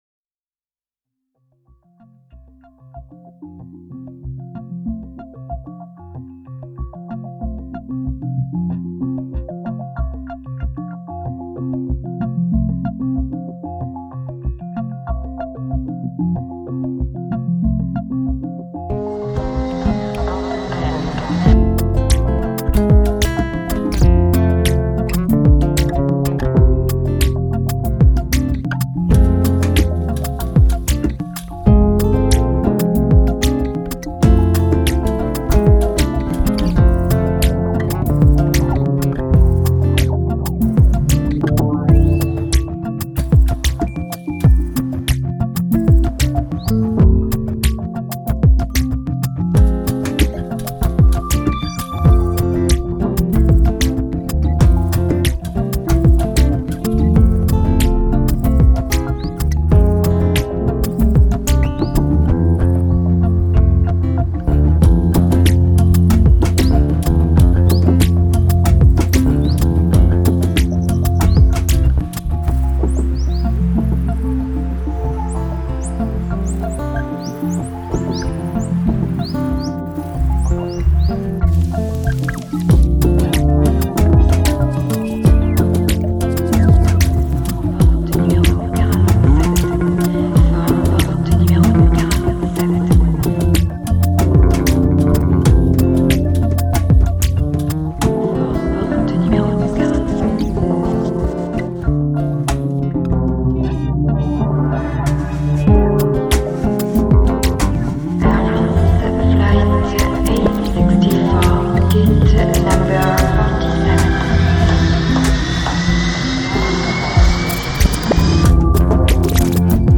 un trio elettroacustico sperimentale che suona seduto